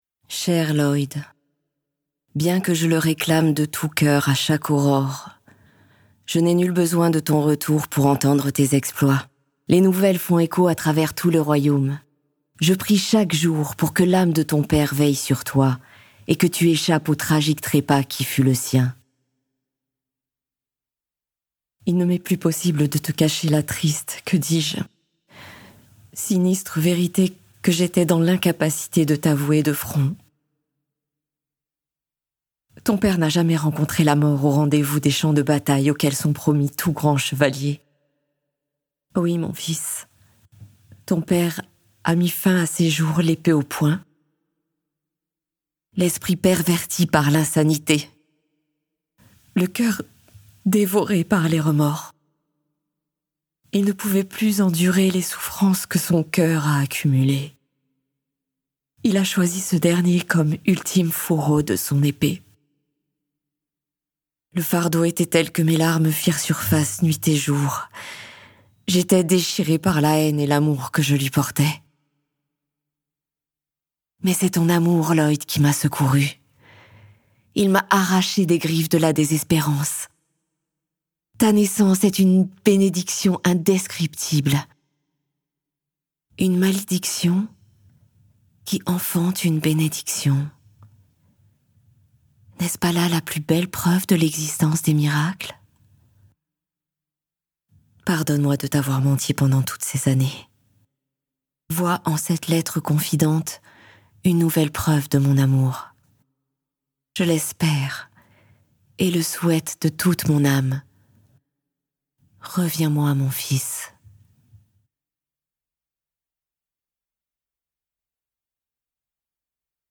Monologue Amélia